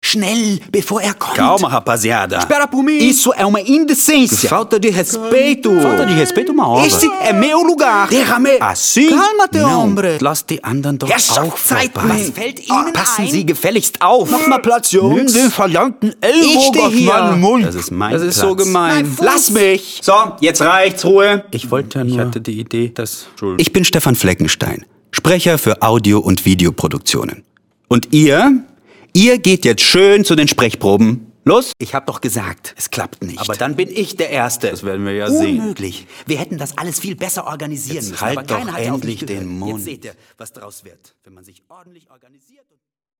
Sprechprobe: Werbung (Muttersprache):
voice over artist: german, brasilian, portuguese.